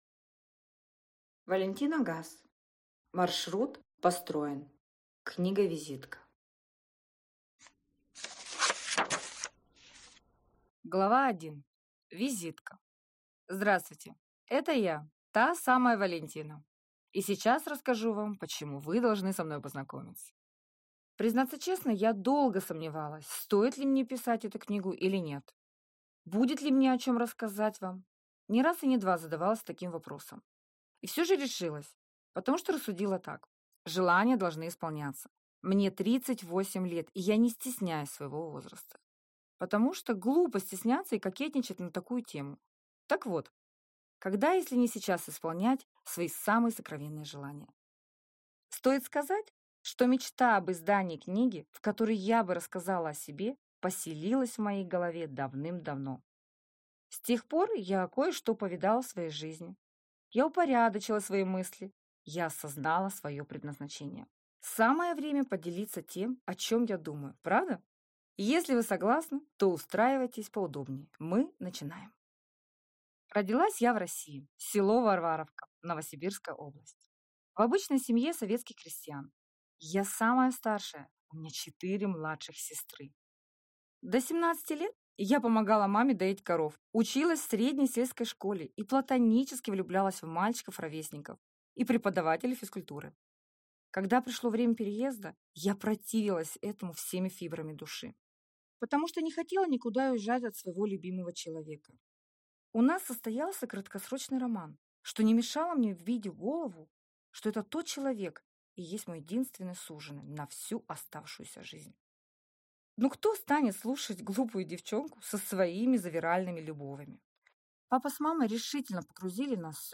Аудиокнига Маршрут построен | Библиотека аудиокниг